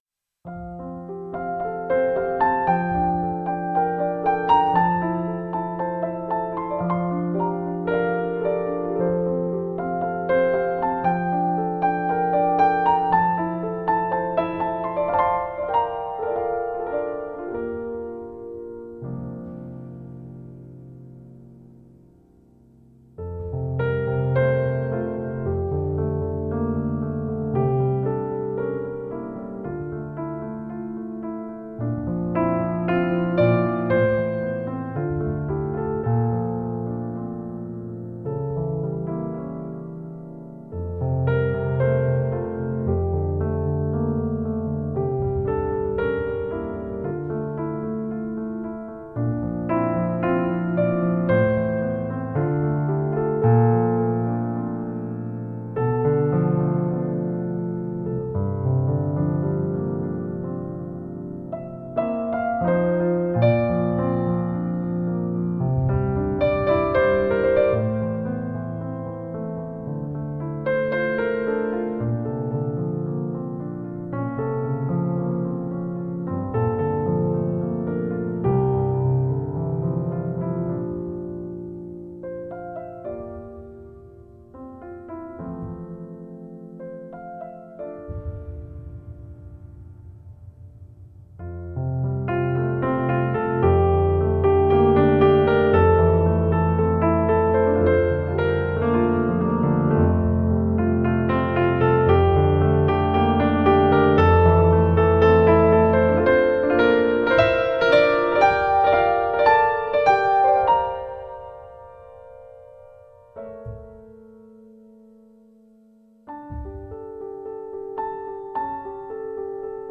ピアノソロ